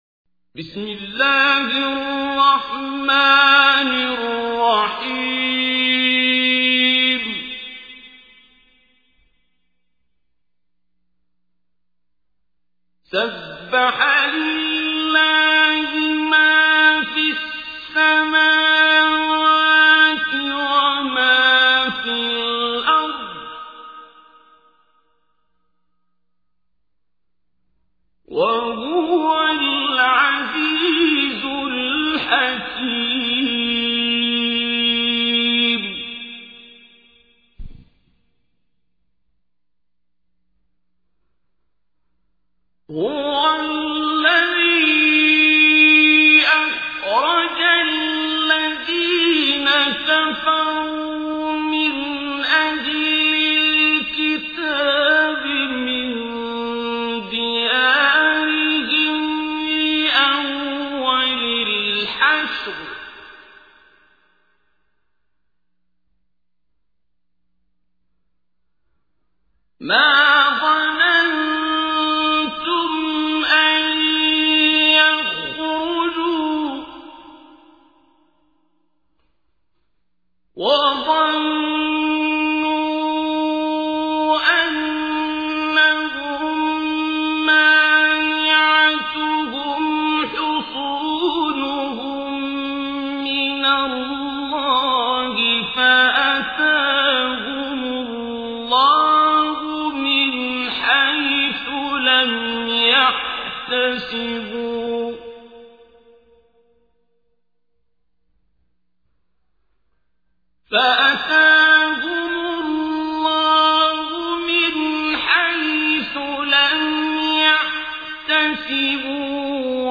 تحميل : 59. سورة الحشر / القارئ عبد الباسط عبد الصمد / القرآن الكريم / موقع يا حسين